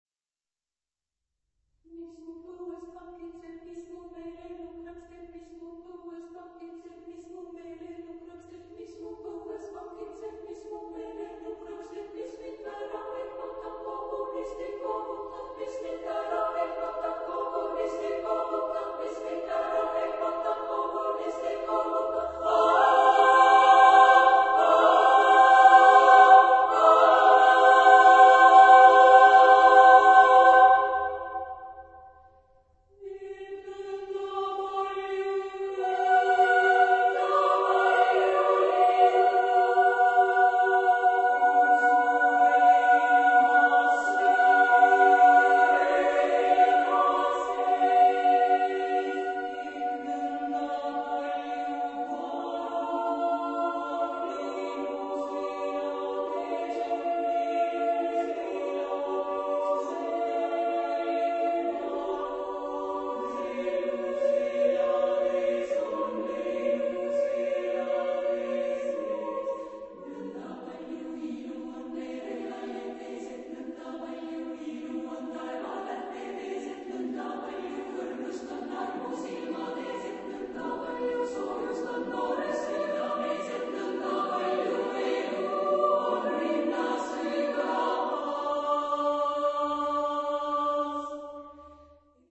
Genre-Style-Form: Secular ; Vocal piece ; fugato
Type of Choir: SSAA  (4 women voices )
Tonality: various